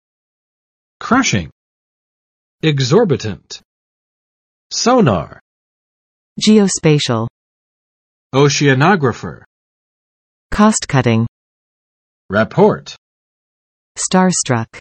[ˋkrʌʃɪŋ] adj. 压倒性的